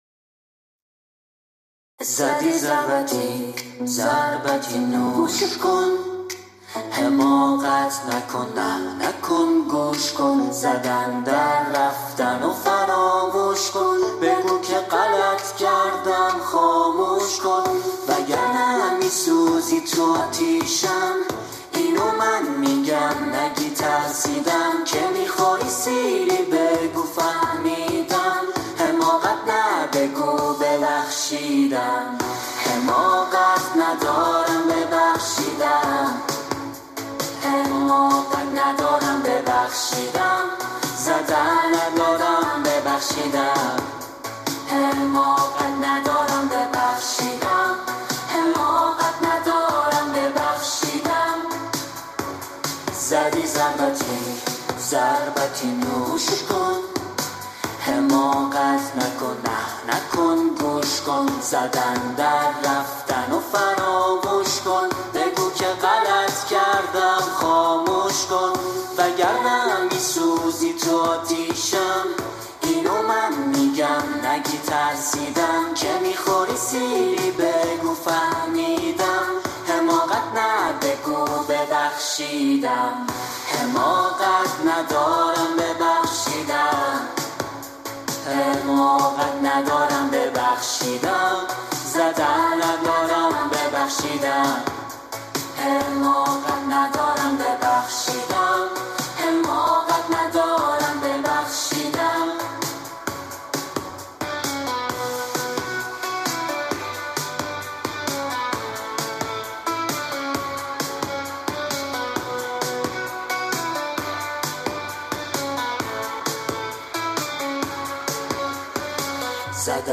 خواننده پاپ